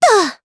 Dosarta-Vox_Landing_jp.wav